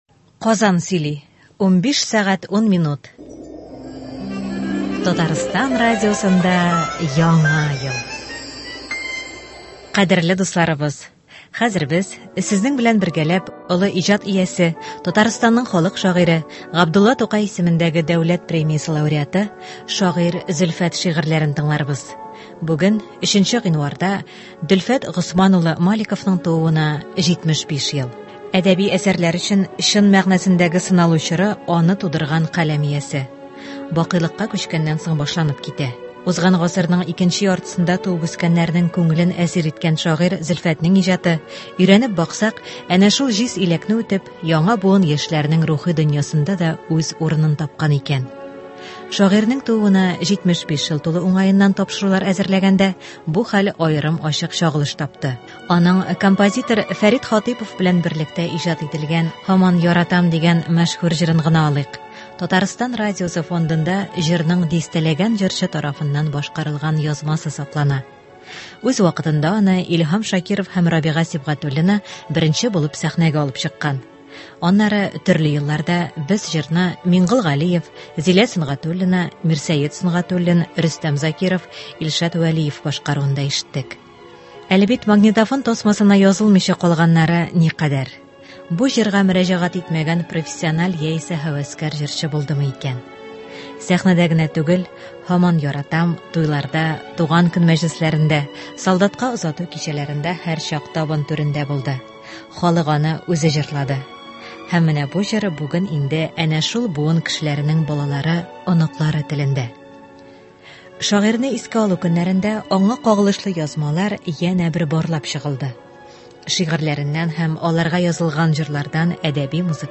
Шагыйрь Зөлфәт әсәрләреннән әдәби-музыкаль композиция.